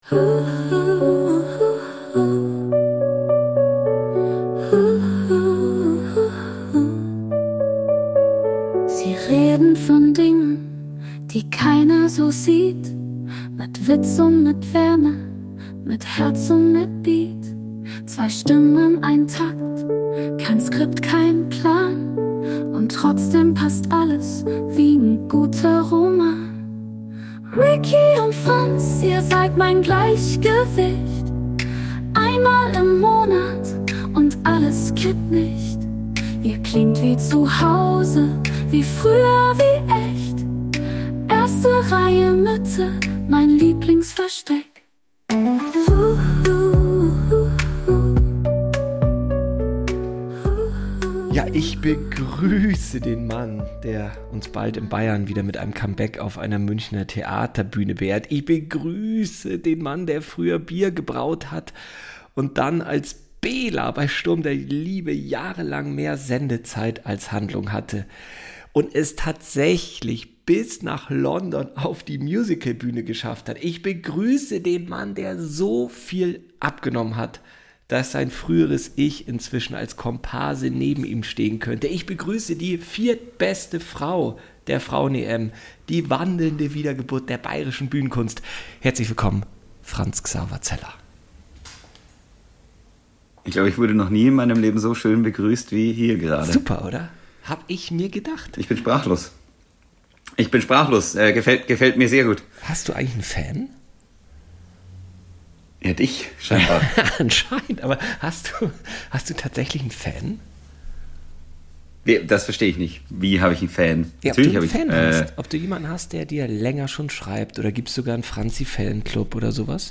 Hier geht es heute nicht um Show, nicht um Pose - sondern um ein echtes Gespräch. Die beiden haben völlig vergessen, dass da ein Mikro mitläuft. Was bleibt, ist pures, unverstelltes Reden. Echt und ungeschnitten.